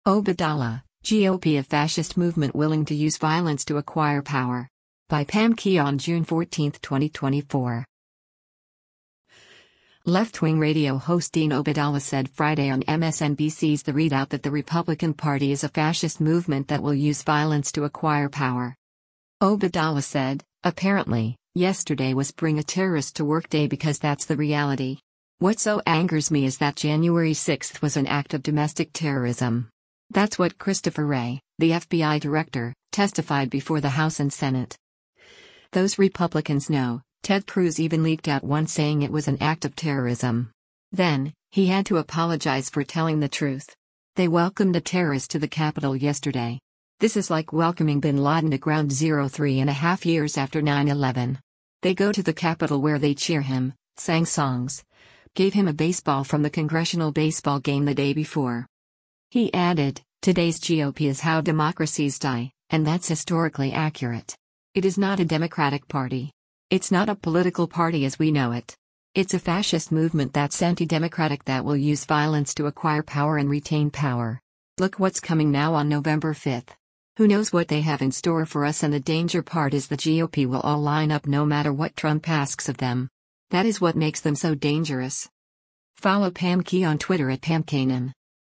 Left-wing radio host Dean Obeidallah said Friday on MSNBC’s “The ReidOut” that the Republican Party is a “fascist movement” that will use violence to acquire power.